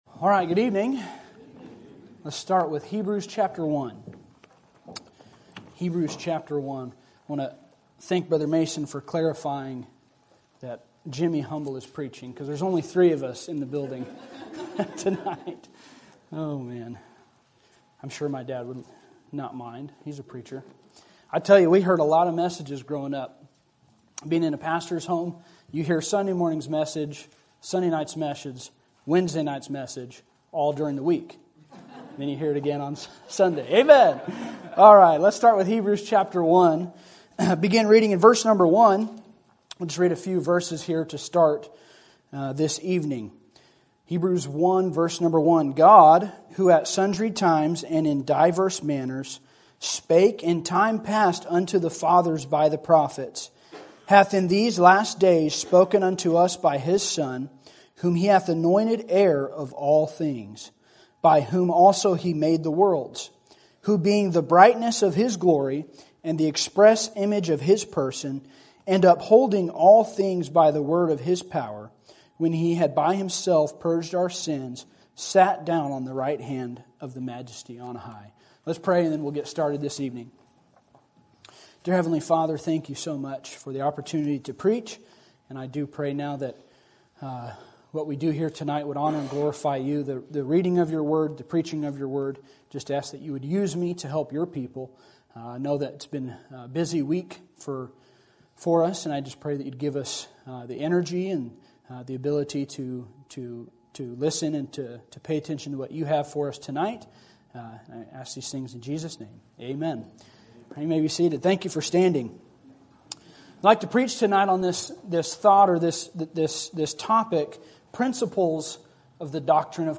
Sermon Topic: General Sermon Type: Service Sermon Audio: Sermon download: Download (20.65 MB) Sermon Tags: Hebrews Christ Doctrine Principles